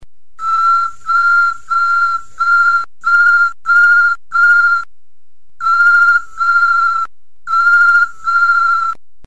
É um piado lindo que pode ser ouvido a centenas de metros do local, principalmente se, no momento, a floresta estiver vivendo um raro momento de silêncio, já que os Tínamus empoleiram quando os diurnos se recolhem e os notívagus despertam. Seus piados vão de sete a onze repetições, emitidos seguidamente.
PIADO de uma fêmea de Major. O macho, no entanto, apesar de piar menos, o faz com grande semelhança, apenas um pouco menos grave e, na maioria das vezes, mais curto.
majorpiado.mp3